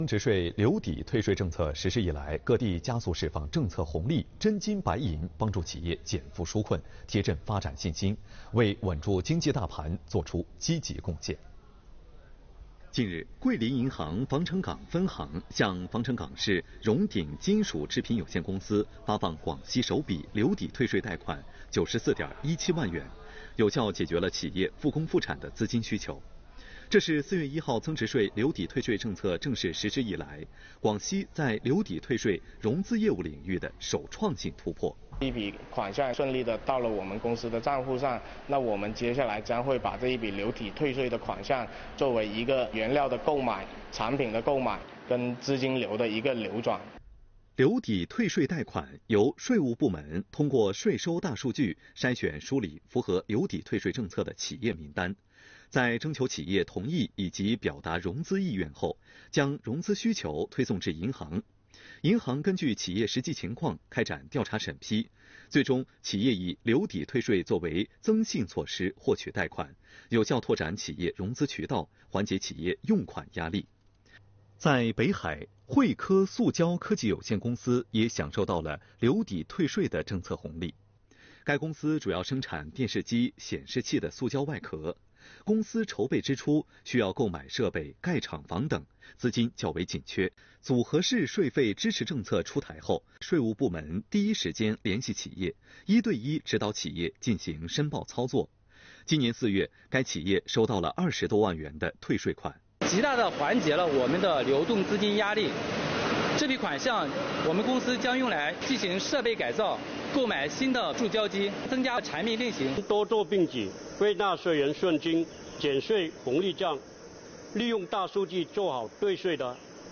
来源：广西广播电视台新闻频道